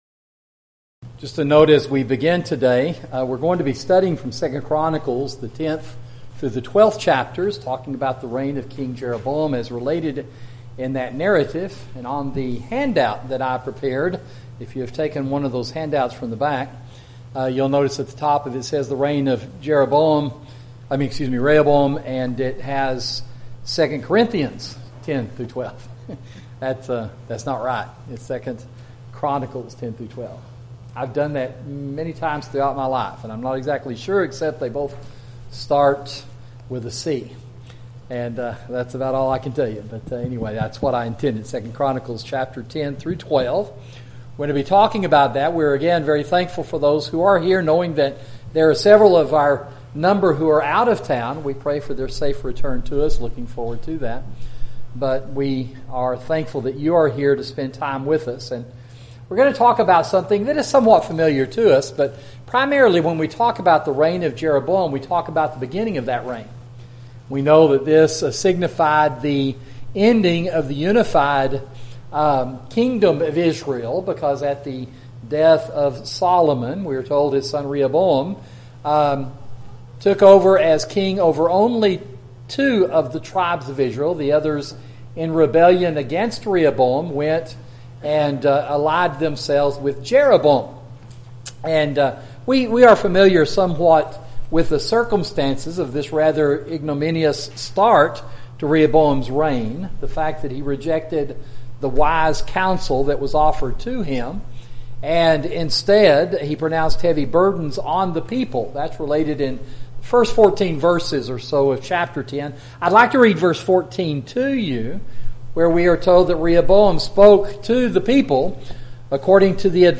Sermon: The Reign of King Rehoboam